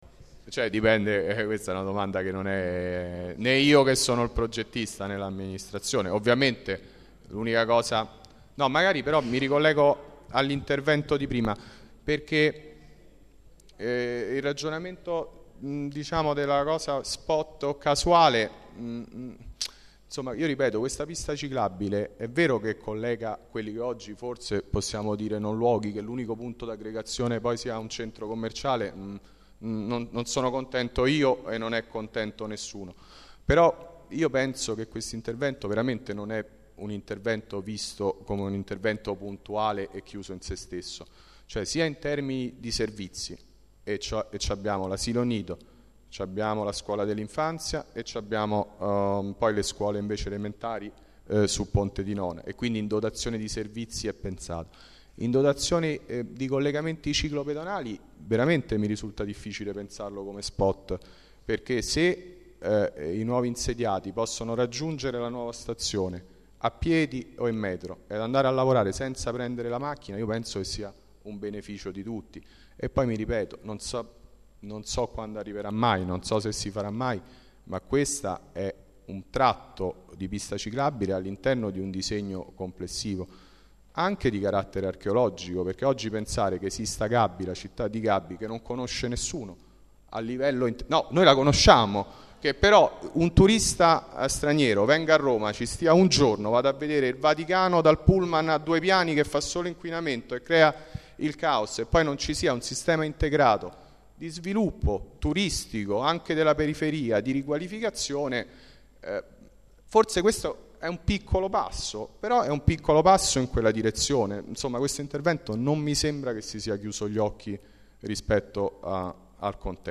Assemblea
Registrazione integrale dell'incontro svoltosi il 4 aprile 2013 presso la sala consiliare del Municipio Roma VIII